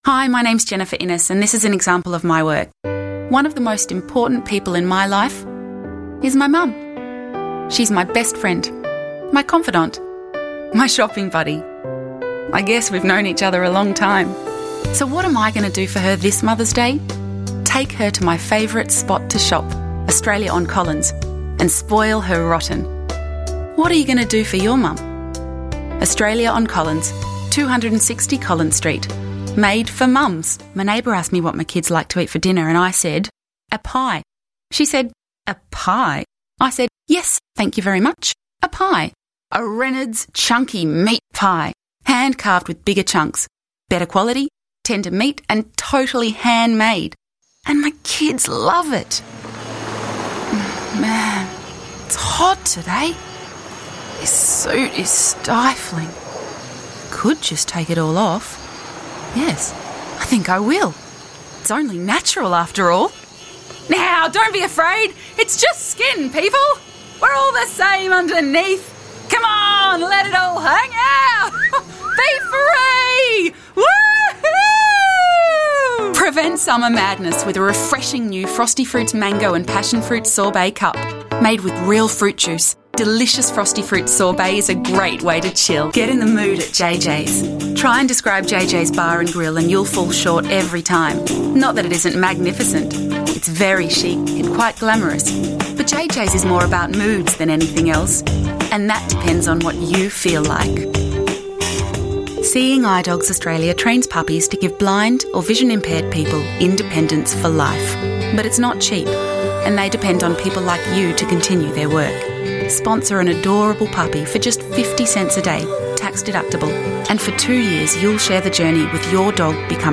Voiceover
Accents: Standard US, Southern US, New York, RP, Modern RP, Yorkshire & others on request